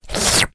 Звук обработки тарелки языком